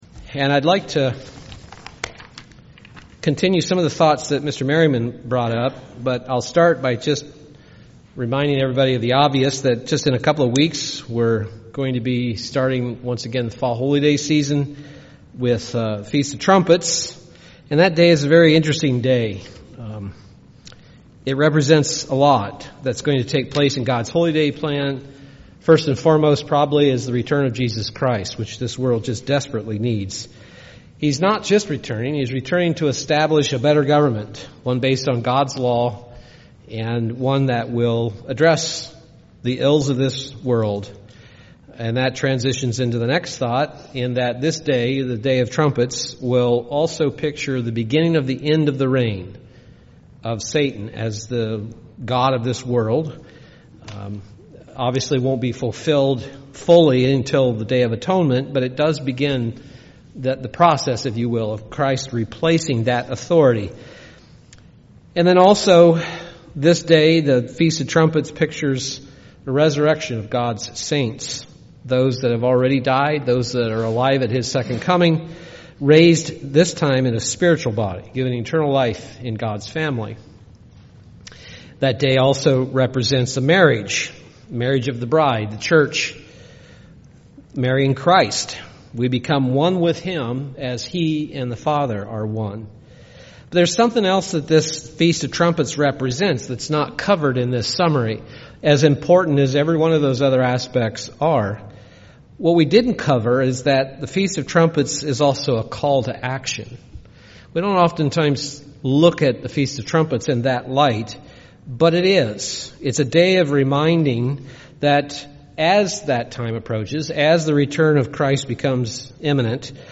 Given in Milwaukee, WI
UCG Sermon Studying the bible?